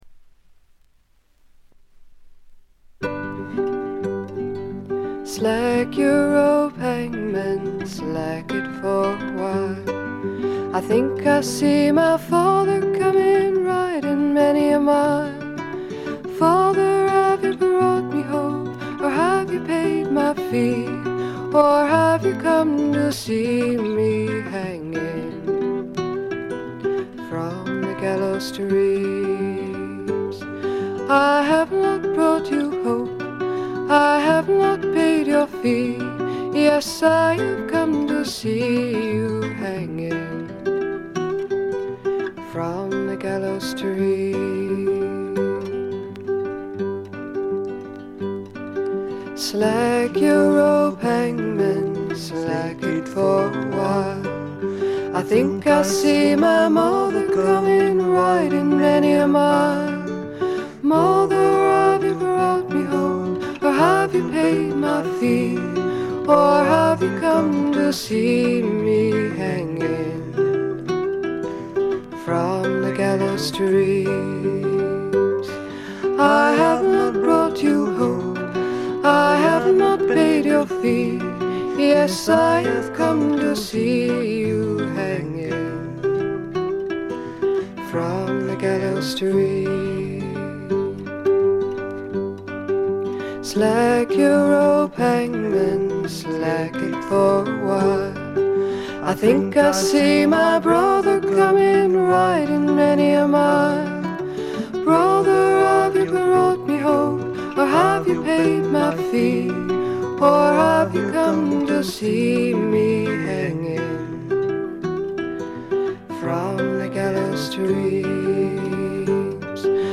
ちょっとハスキーな美声ではかなげに歌われる宝石のような歌の数々。
試聴曲は現品からの取り込み音源です。
guitar
flute
dobro guitar